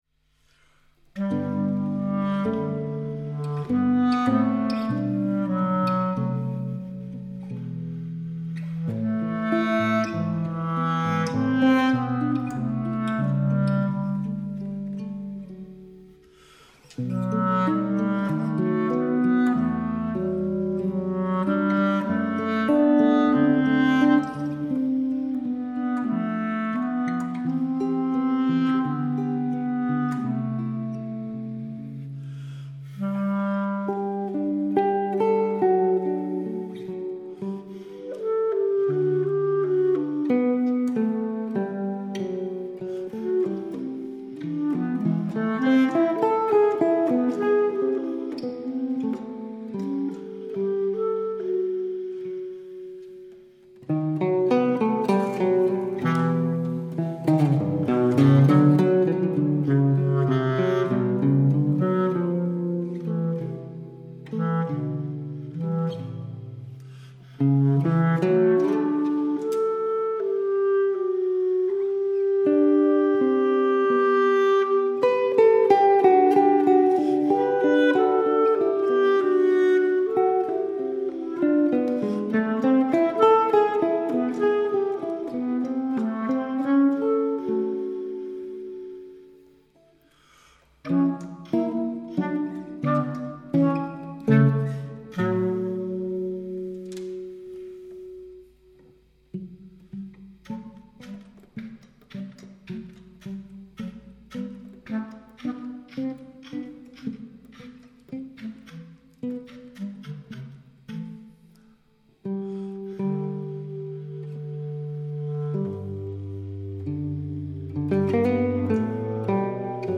Guitaristen
folkemusikeren
Med nænsomme arrangementer, og en enkelhed i udtrykket, skaber duoen et rum til fordybelse og eftertanke. Her får velkendte sange som Den Blå Anemone, Tit er jeg glad og Hil dig frelser og Forsoner nyt liv – ikke som fællessang, men som instrumentale fortolkninger, hvor tonerne taler på deres egne præmisser.
I kirkens akustik og ro falder musikken naturligt til rette. Musikken bevæger sig i det enkle og inderlige og minder os om, hvor stærkt melodien i sig selv kan bære bådefortælling og følelse.